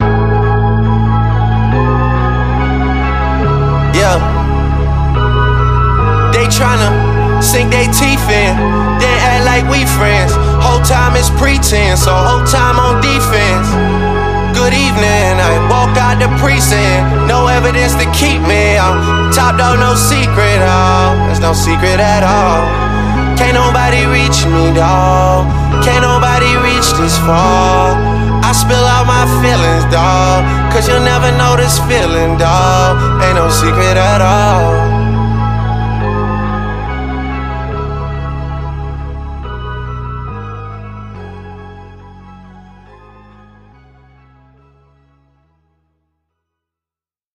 Each song has a strong beat and powerful lyrics.